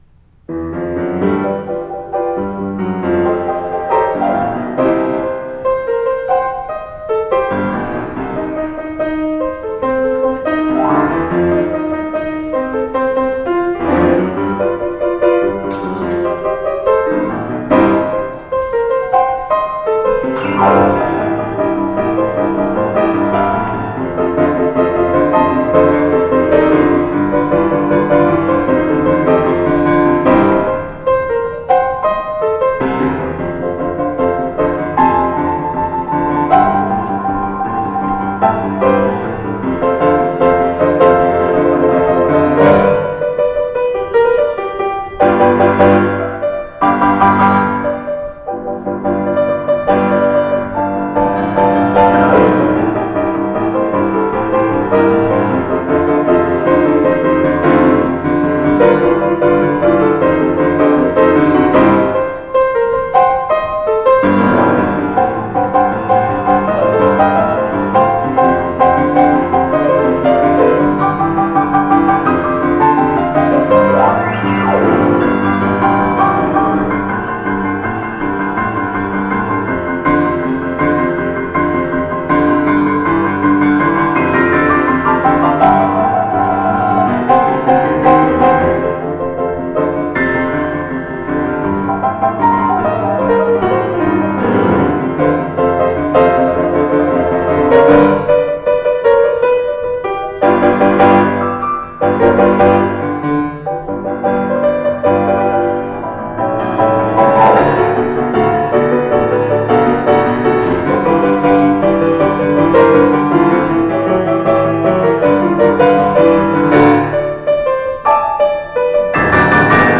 [Fuusm-l] A few boisterous pieces for balance
some rowdier tunes.